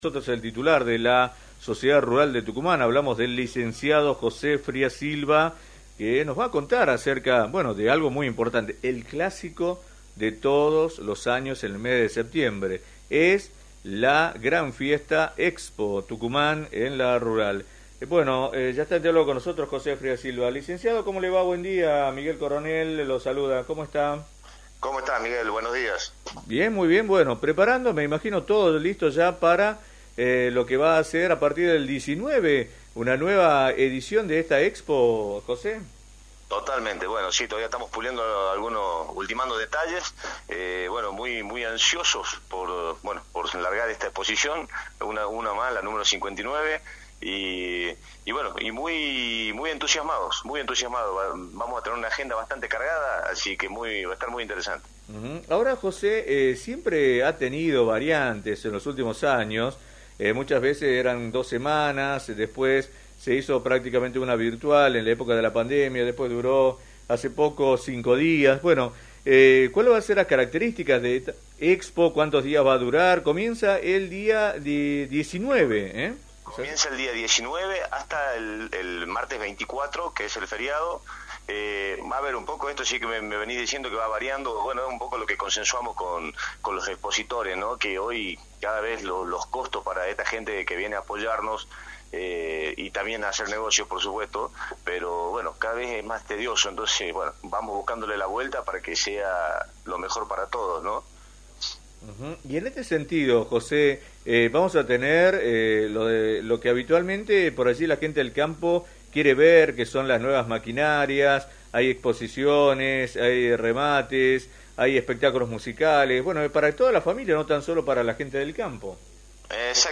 confirmó en “Metro Club”, por la 89.1, que la Expo Tucumán se realizará entre el 19 y el 24 de septiembre en la sede de la Sociedad Rural de Tucumán.